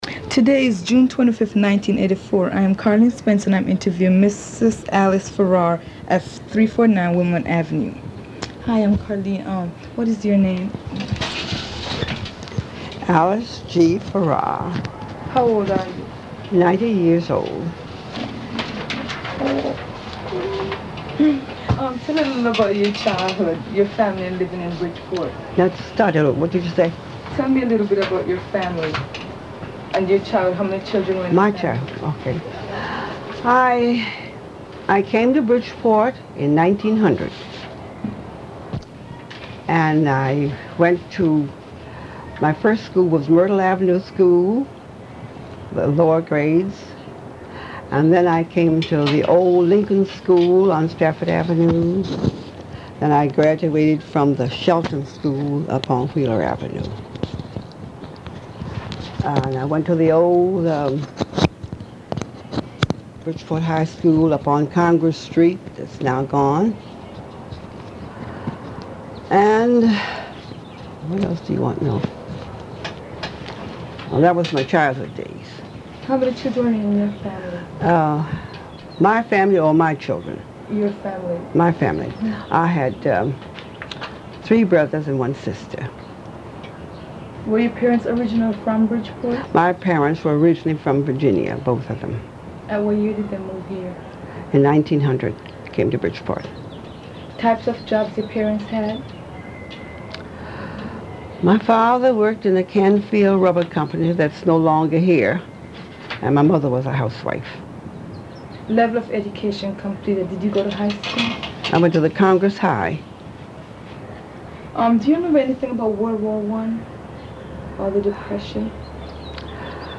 Bridgeport Living History : Oral Histories Conducted by Bridgeport Youth in 1984